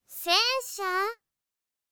TTS comparison (Pitch Accent)
Cevio AI (Satou Sasara, CV: Minase Inori)
01_Sato-Sasara_戦車.wav